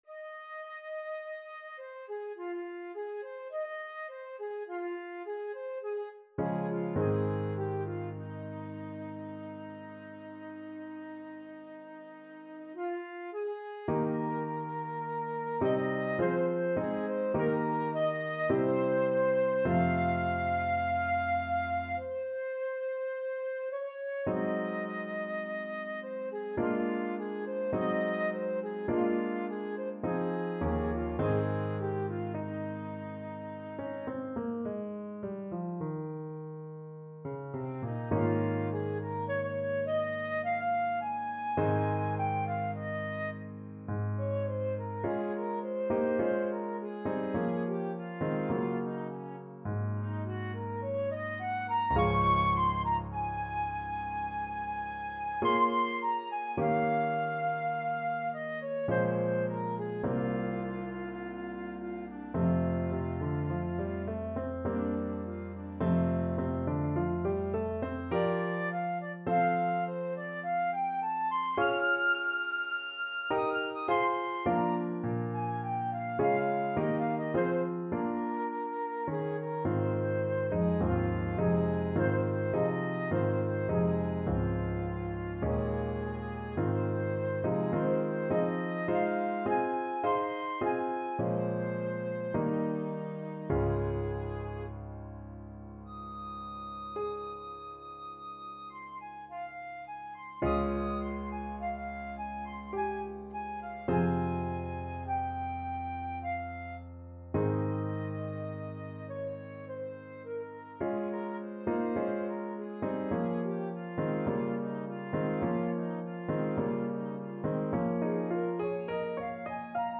flute and piano